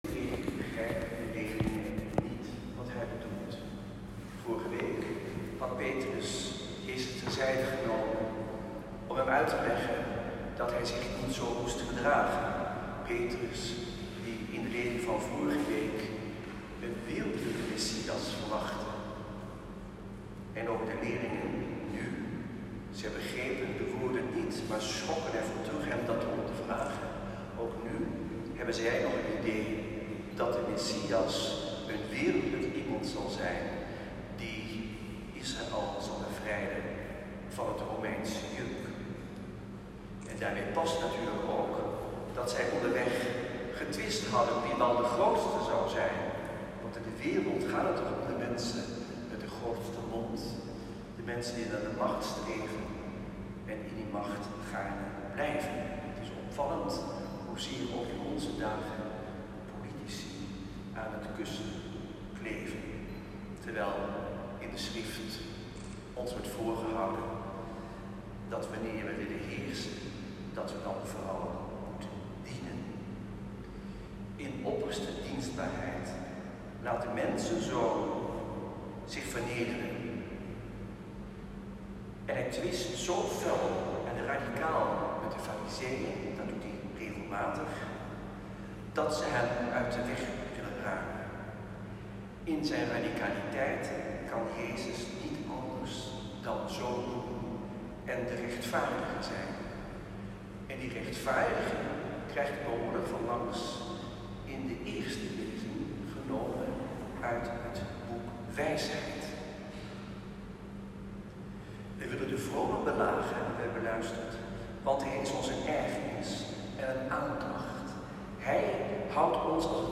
Celebrant: Antoine Bodar
Preek